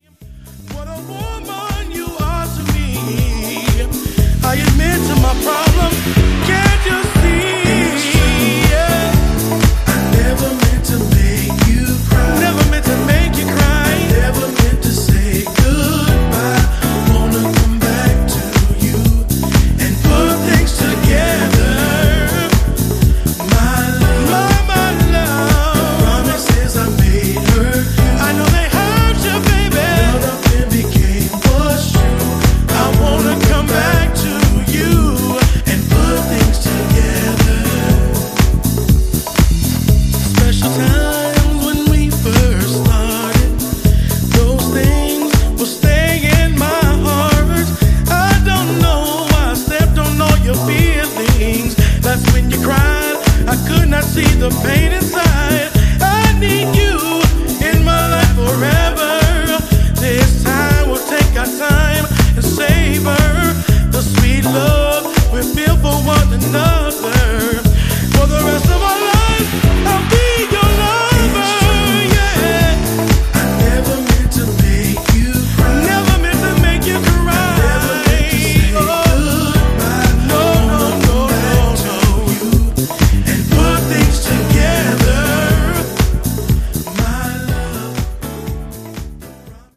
house music
a track that should be found in every soulful house DJ’s set